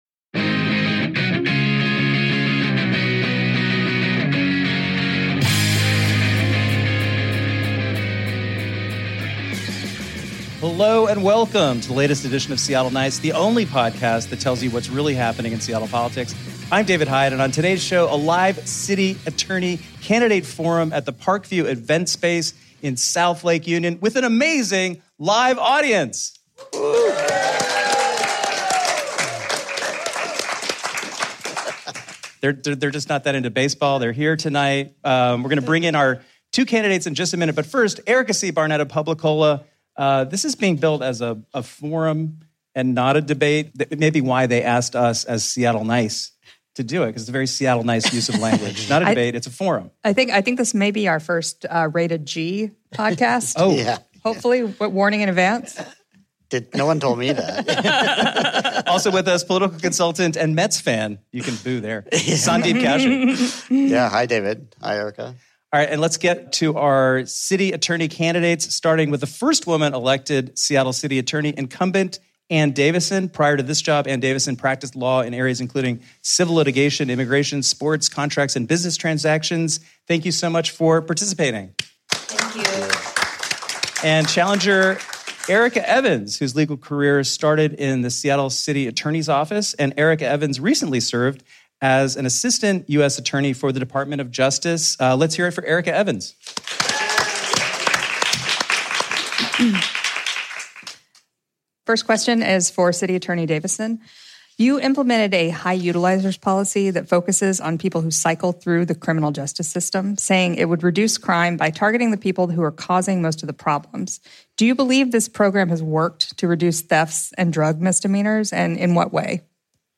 And due to a production error at the venue, Ann Davison’s voice is harder to hear than everyone else’s; please excuse the poor sound quality.
nicest-city-attorney-debate.mp3